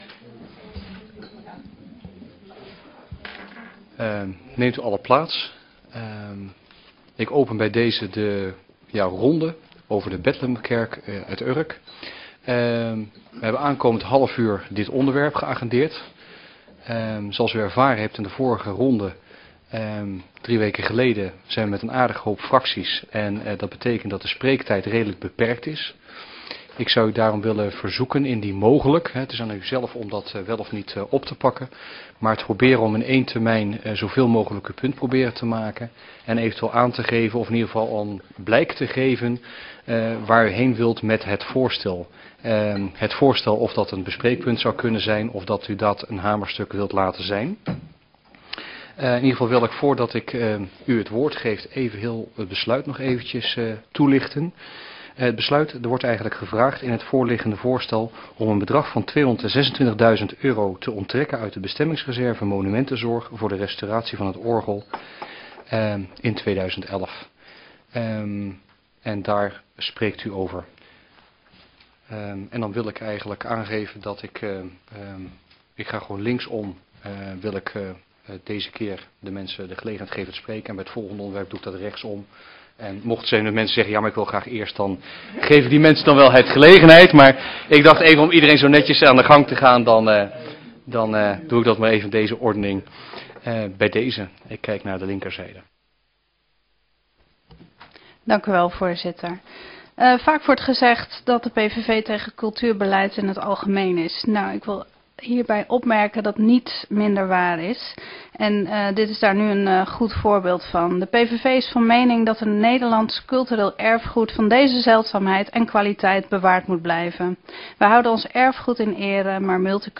Download de volledige audio van deze vergadering
Locatie: Statenzaal